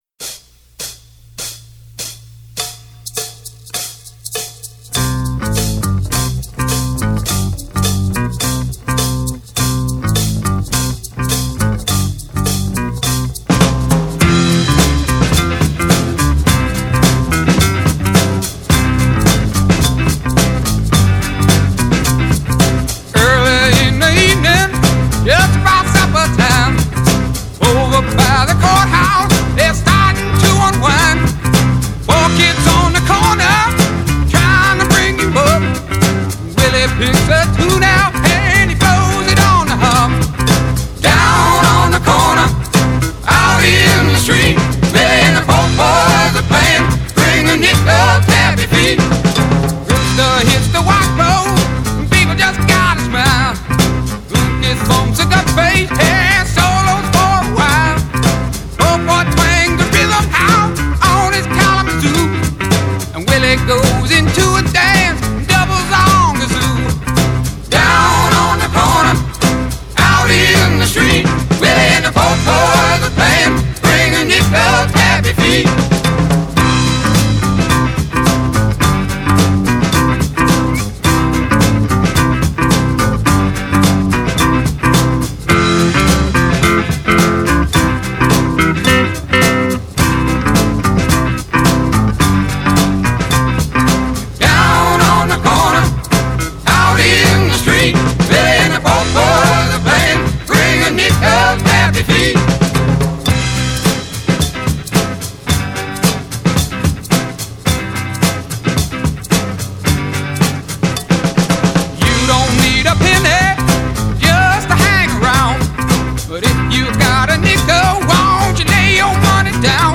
Rock, Southern Rock, Blues Rock